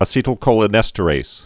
(ə-sētl-kōlə-nĕstə-rās, -rāz)